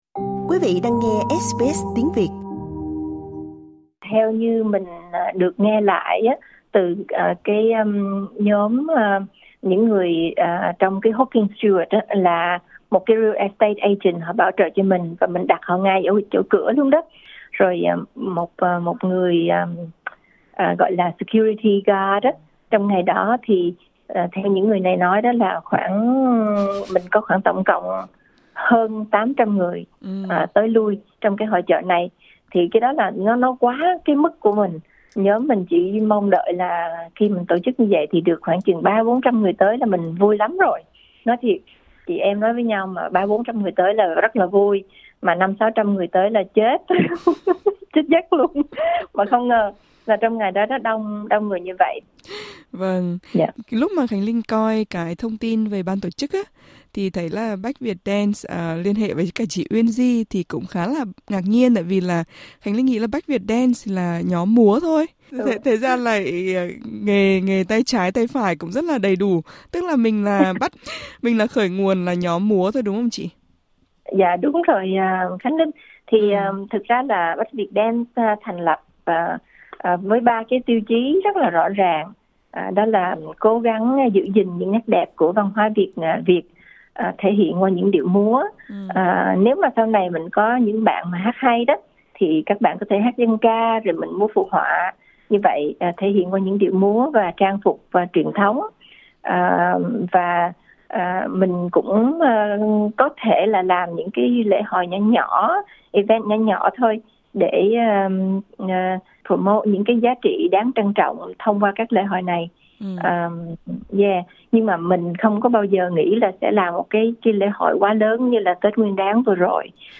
Xin mời quý vị bấm vào hình để nghe cuộc trò chuyện.